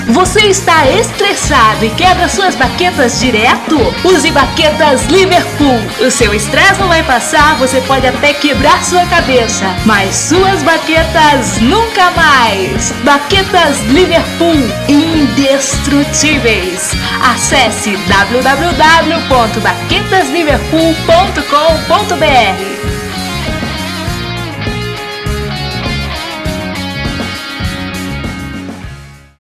Gente, baquetas Liverpool é mais um produto fictício, mas, está aí nosso spot publicitário.
O original para o programa de rádio estilo humorístico é o primeiro, mas, a pedidos, gravei uma outra versão com background do U2!
baquetas-versão-U2.mp3